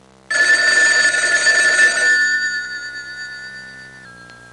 Telephone Sound Effect
Download a high-quality telephone sound effect.
telephone-2.mp3